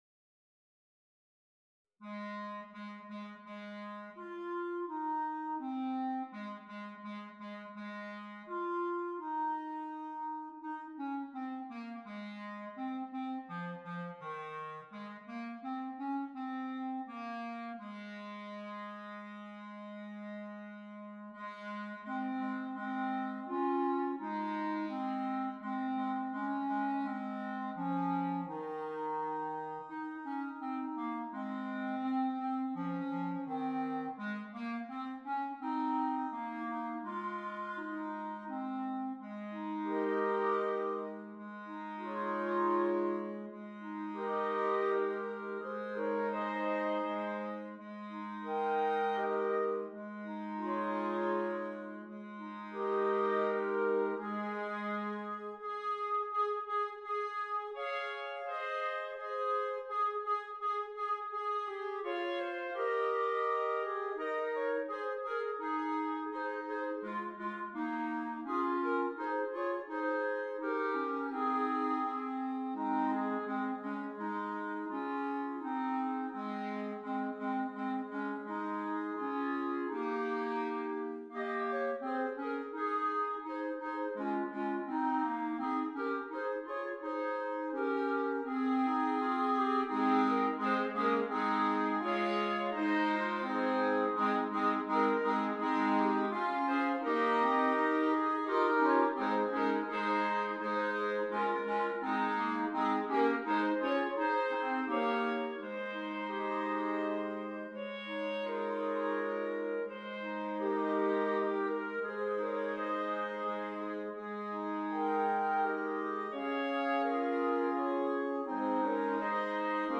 6 Clarinets
Traditional Carol
This piece is flowing and smooth, quiet and reflective.